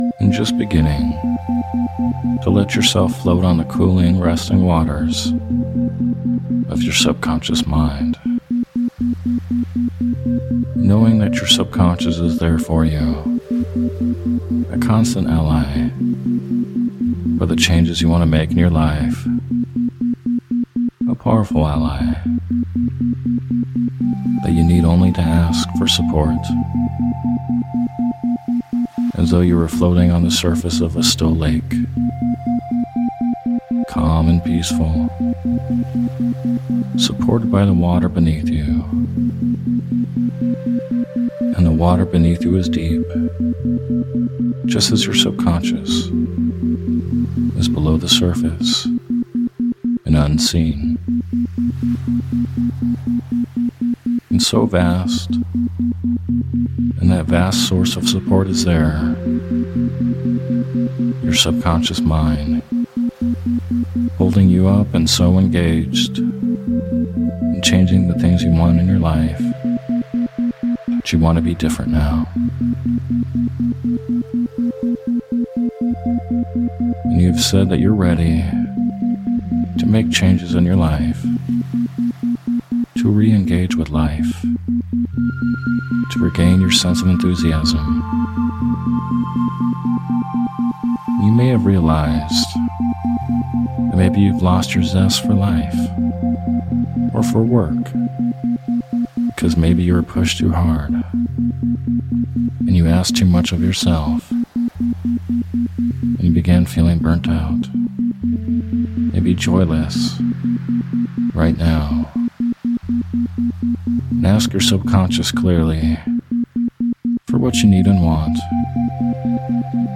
Sleep Hypnosis For Overcoming Burnout With Isochronic Tones
In this guided meditation or hypnosis audio, you’ll be guided in helping the symptoms of feeling burnt out.